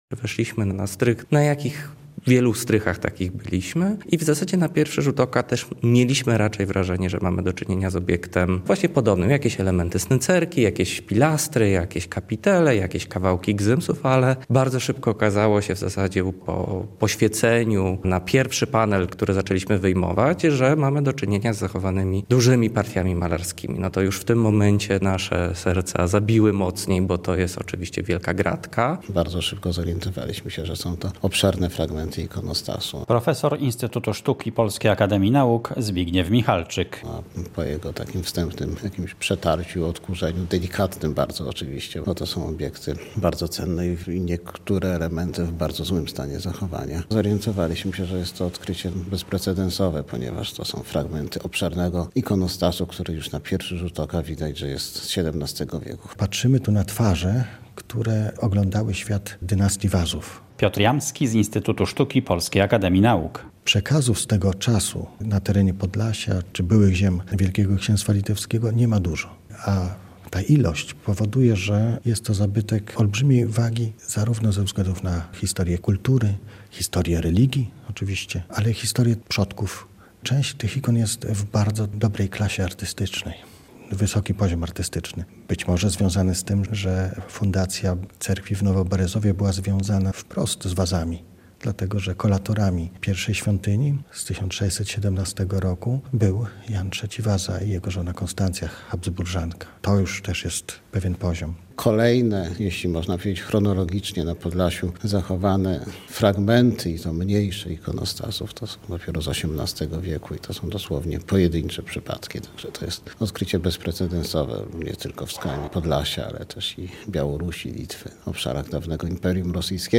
W środę (11.10) w Pałacu Branickich w Białymstoku zaprezentowano taki skarb – monumentalny obiekt, bo ponad 20 fragmentów ikonostasu z XVII wieku.